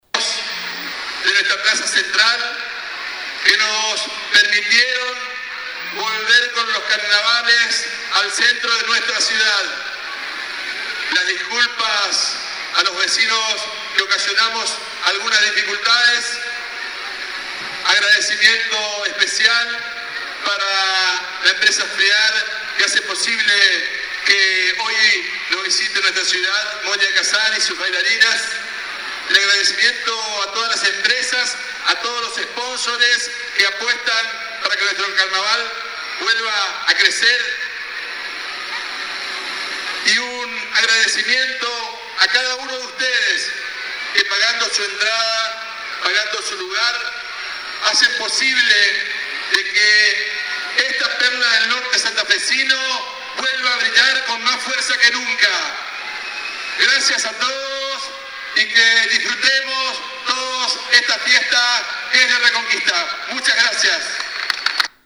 Durante la ceremonia de inauguración de la edición 2019, el Intendente de Reconquista, Enri Vallejos detalló que “se trabajó mucho para poner a punto el corsódromo sobre calle Obligado, para que la gente esté cómoda y pueda vivir el carnaval”.
Audio discurso del Intendente Vallejos